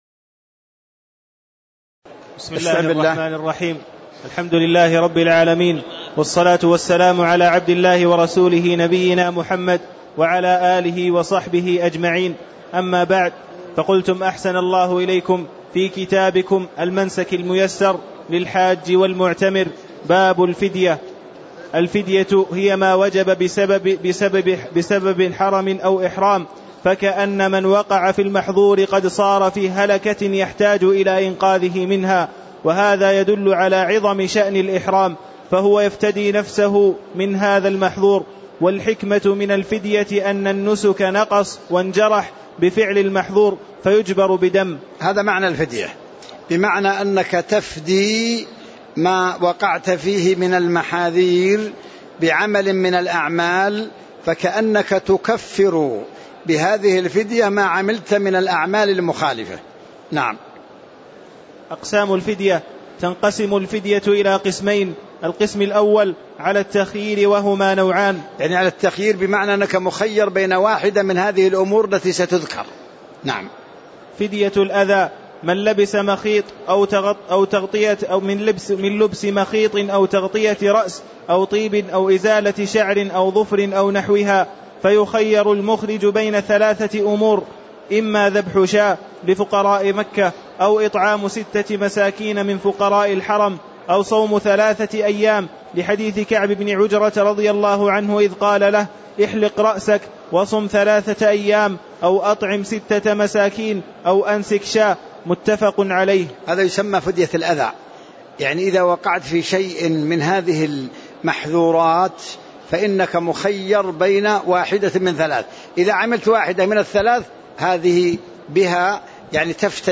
تاريخ النشر ١٦ ذو القعدة ١٤٣٦ هـ المكان: المسجد النبوي الشيخ